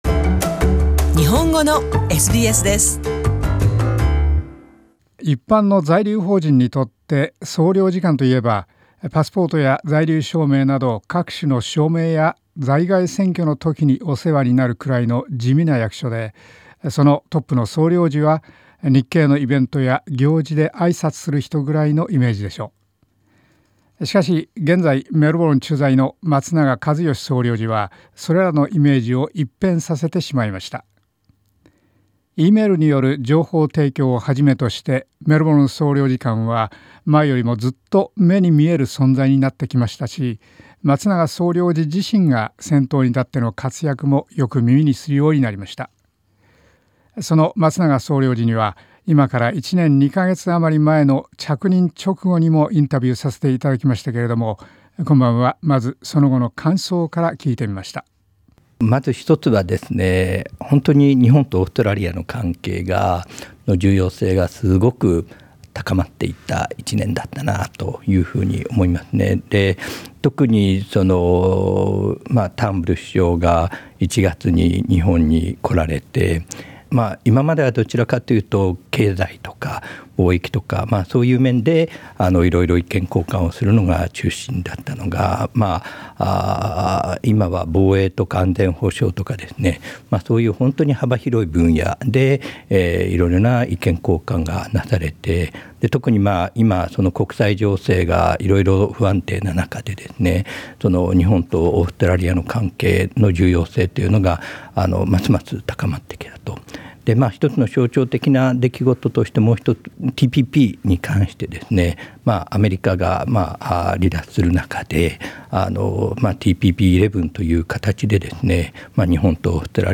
メルボルン着任から１年余り経った松永一義メルボルン総領事に、改めてオーストラリアのことや、日本人コミュニティーのことなどをお聞きした。１年前のインタビューのフォローアップの前半。